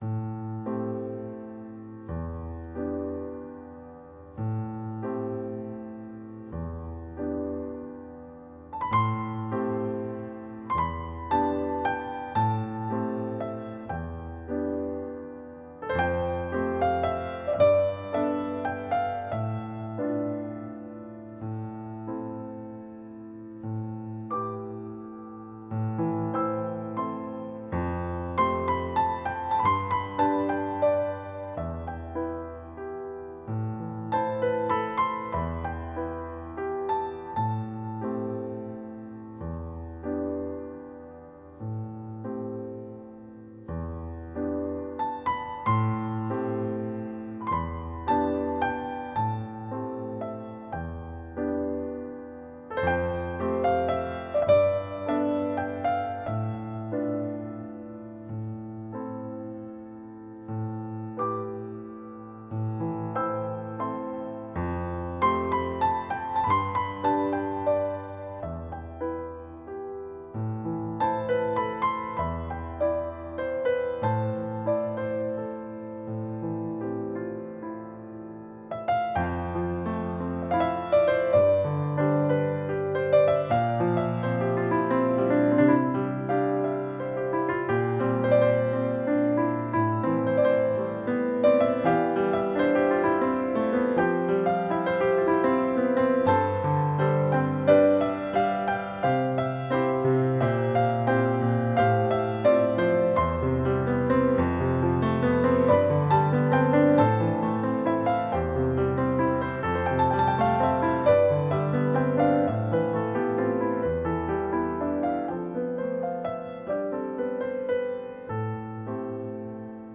这是一张恋爱中的人创作出的浪漫爱情钢琴曲辑~